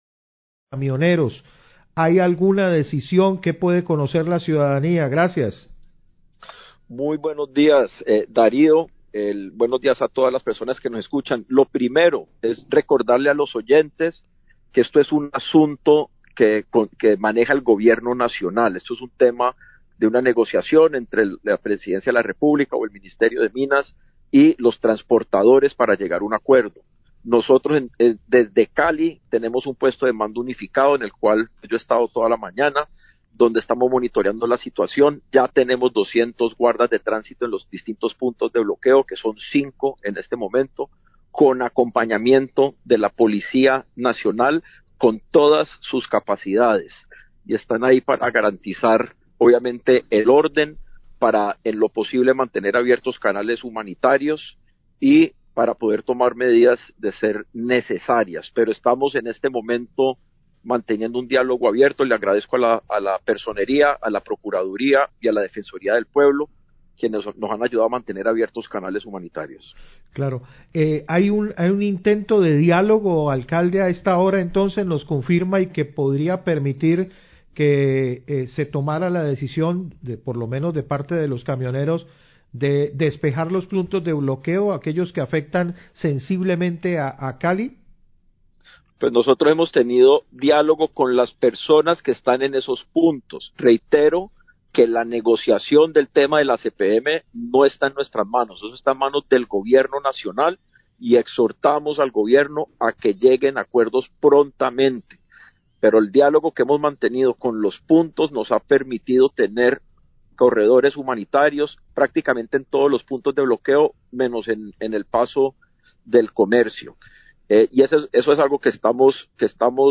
Radio
Entrevista con el Alcalde de Cali, Alejandro Eder, quien habla del monitoreo del paro de camioneros y el diálogo que se mantiene con ellos. Agrega que el tema del precio del ACPM es resorte del gobierno nacional.